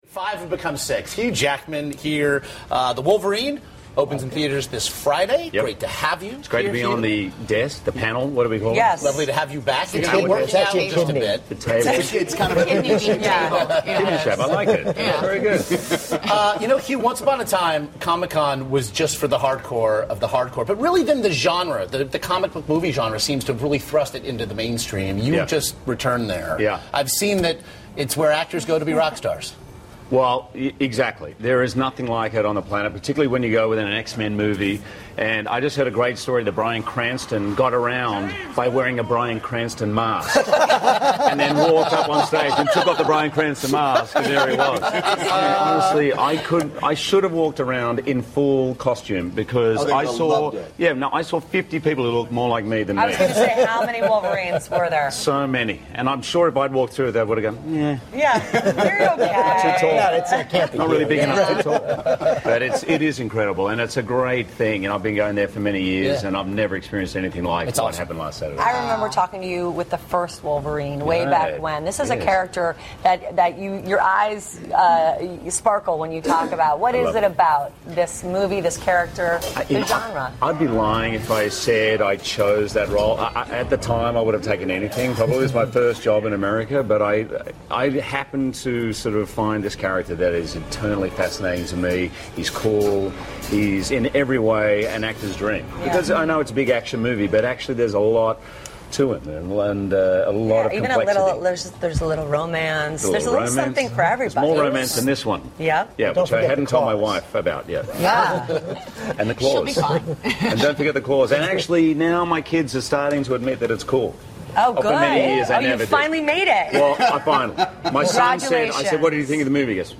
访谈录 2013-07-28&07-30 “金刚狼”休·杰克曼 听力文件下载—在线英语听力室